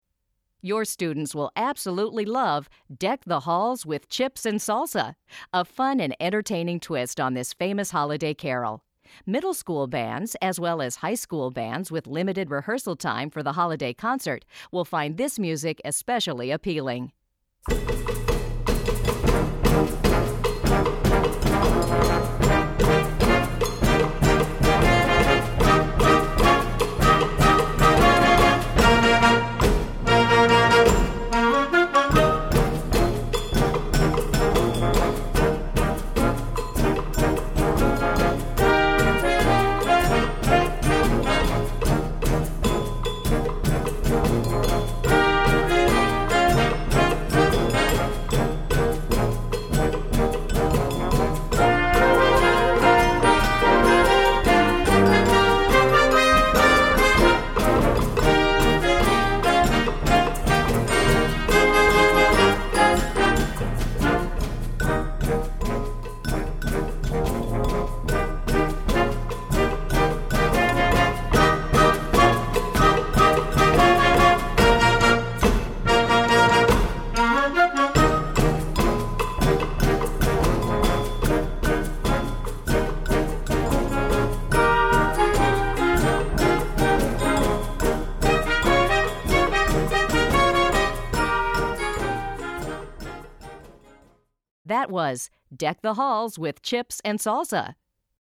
Besetzung: Blasorchester
with a bright Latin beat